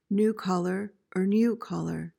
PRONUNCIATION:
(NOO/NYOO-KOL-uhr)